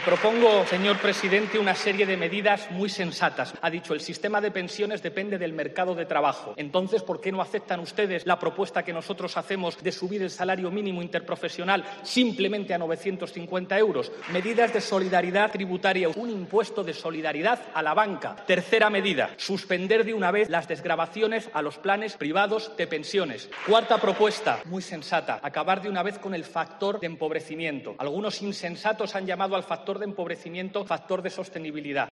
Pablo Iglesias en el Congreso